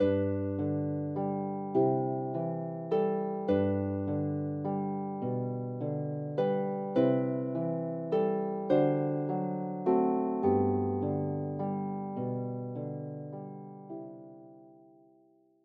” is for solo lever or pedal harp.